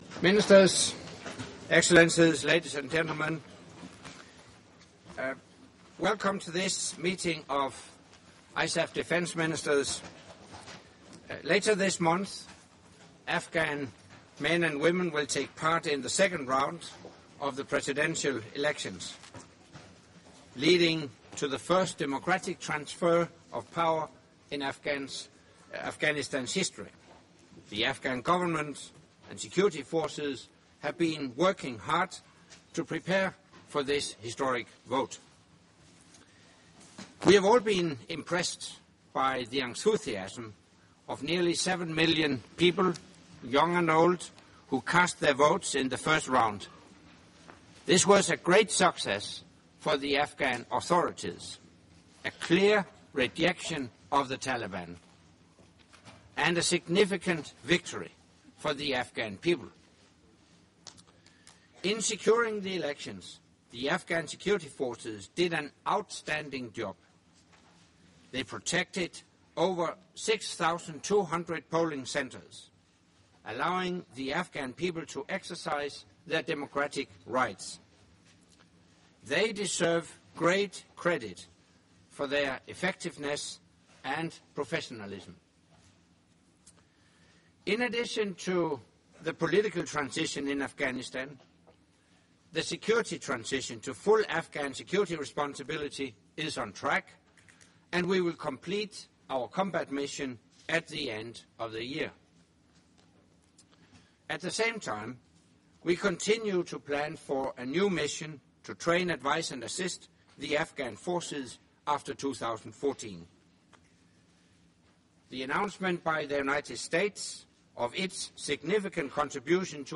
Public opening remarks by NATO Secretary General Anders Fogh Rasmussen at the meeting of the North Atlantic Council with non-NATO ISAF Contributing Nations